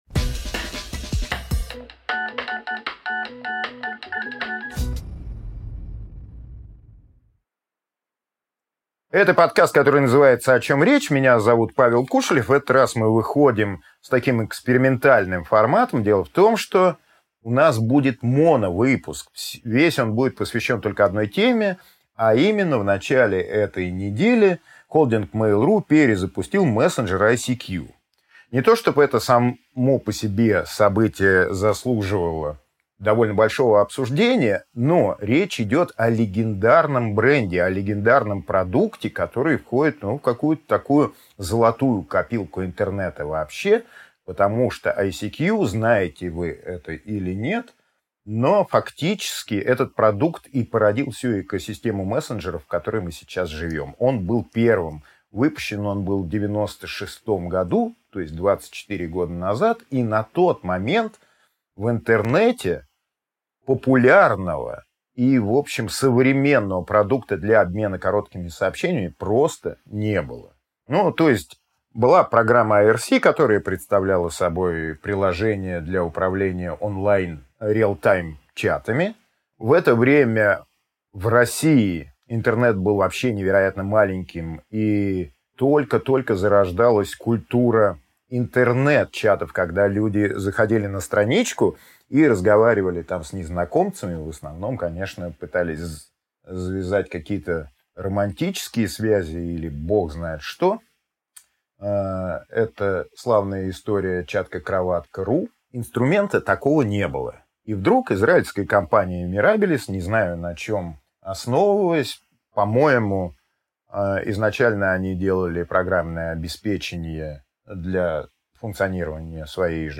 Аудиокнига Самый первый мессенджер в мире - всё про ICQ | Библиотека аудиокниг